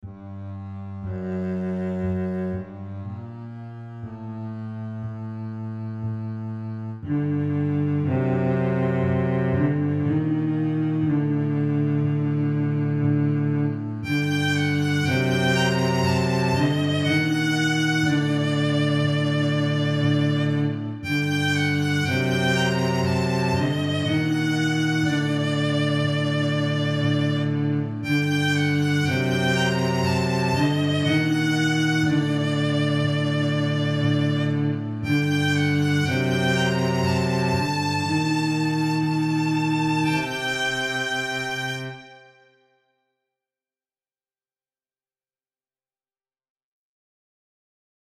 Pieza para trío de cuerda (nostálgica)
melodía
nostalgia
rítmico
sintetizador
Sonidos: Música